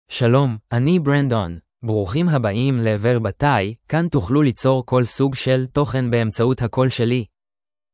MaleHebrew (Israel)
Brandon is a male AI voice for Hebrew (Israel).
Voice sample
Male
Brandon delivers clear pronunciation with authentic Israel Hebrew intonation, making your content sound professionally produced.